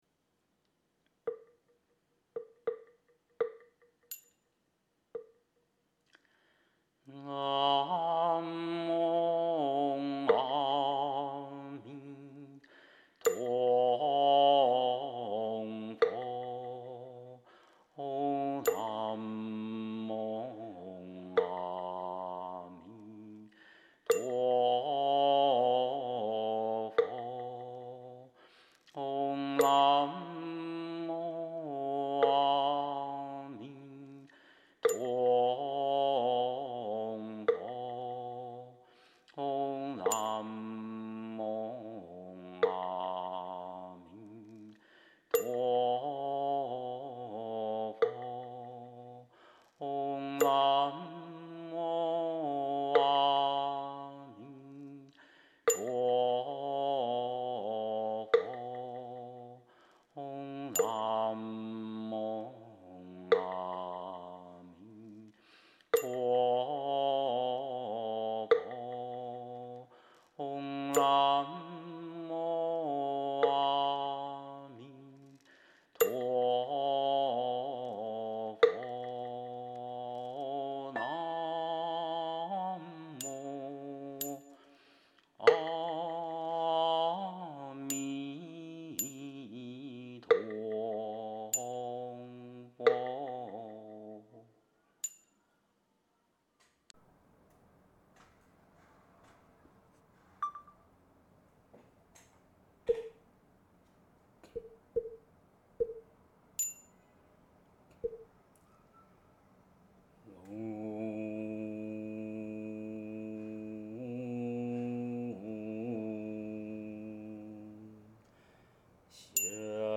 3、《弥陀法会》梵呗仪轨及教学音档 佛教正觉同修会_如来藏网